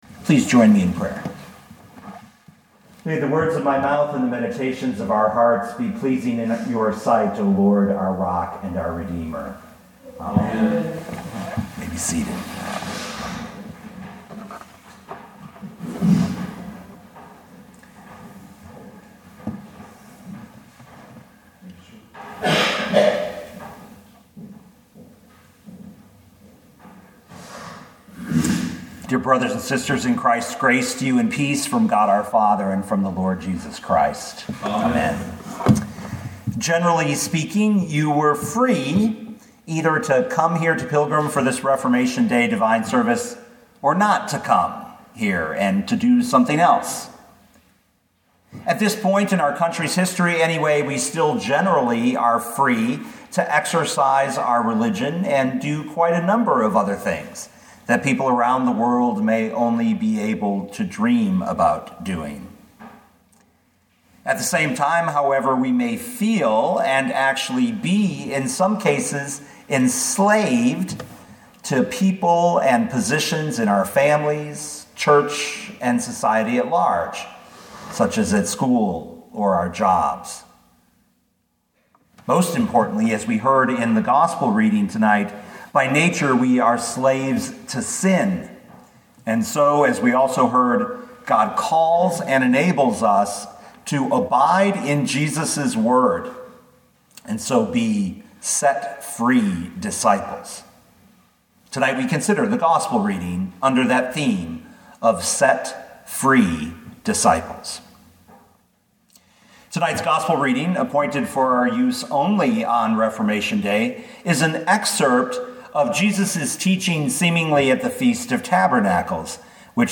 2019 John 8:31-36 Listen to the sermon with the player below, or, download the audio.